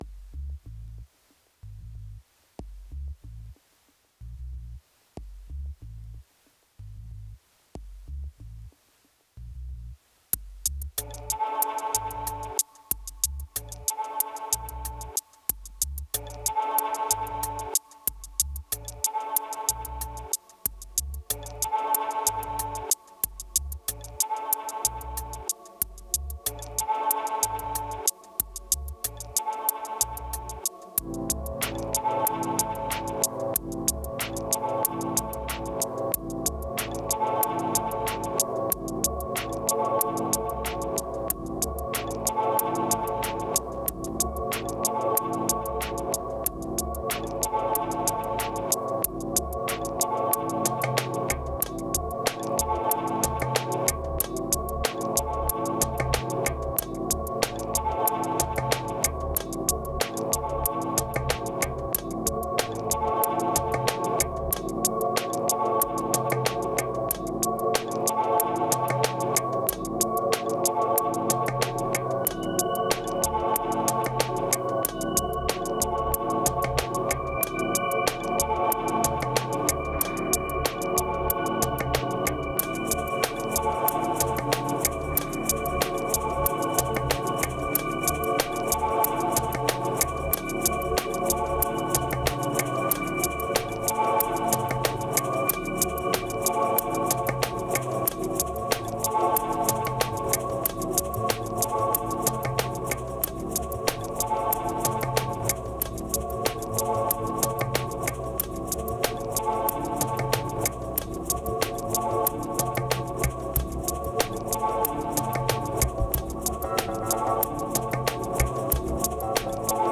3015📈 - 87%🤔 - 93BPM🔊 - 2017-02-11📅 - 998🌟